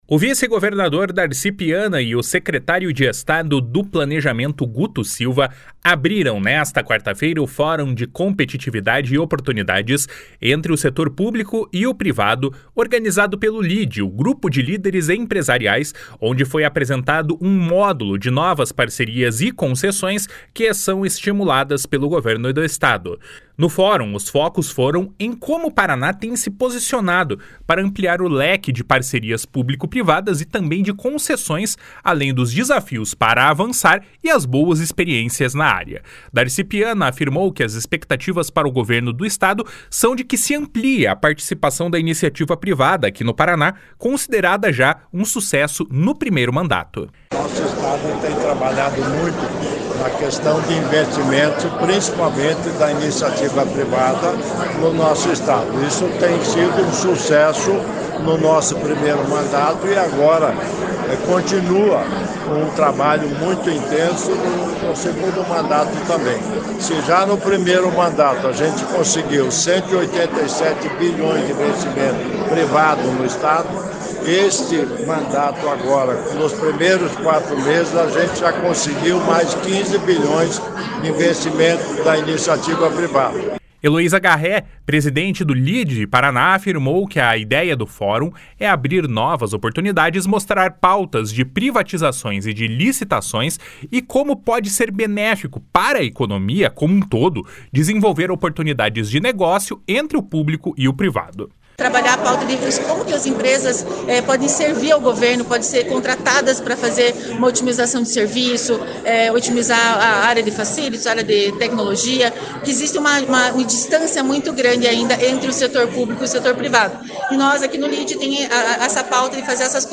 // SONORA DARCI PIANA //